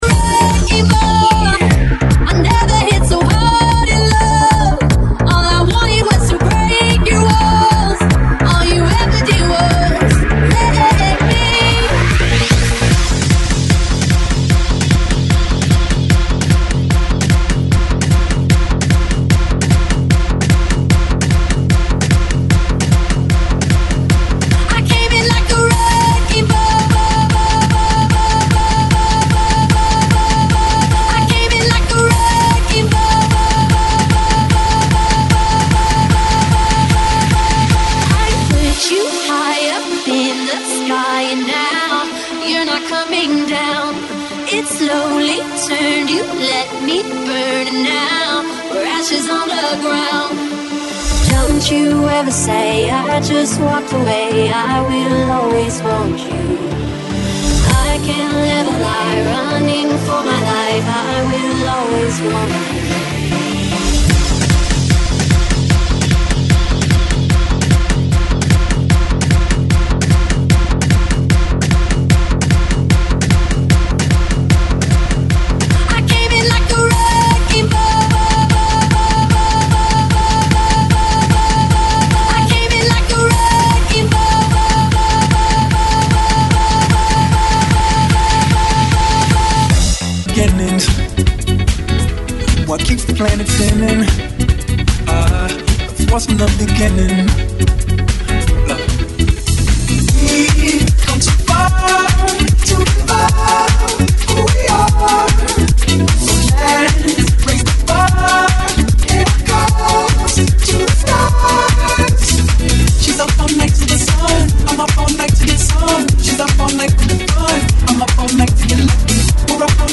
GENERO: POP INGLES